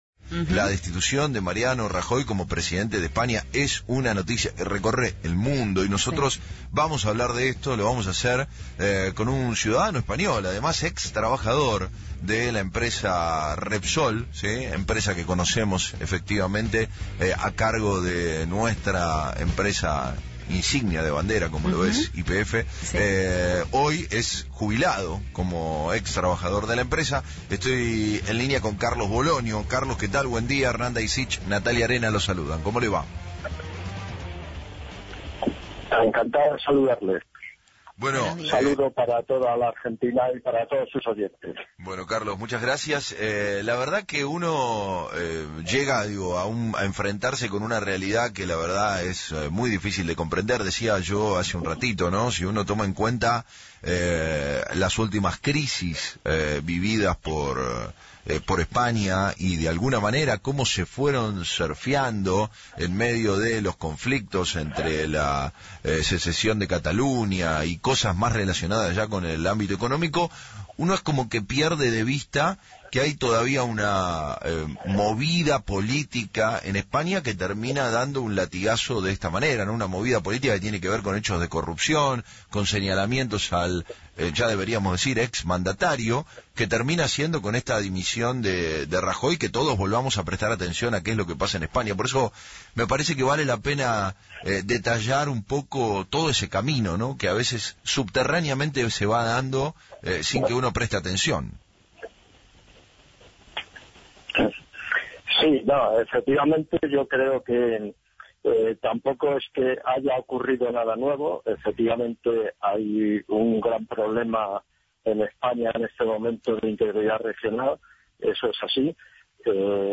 en diálogo con FRECUENCIA ZERO